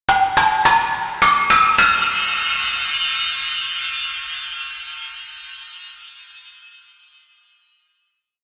Scary Instrumental Swoosh